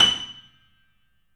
Index of /90_sSampleCDs/E-MU Producer Series Vol. 5 – 3-D Audio Collection/3D Pianos/BoesPlayHardVF04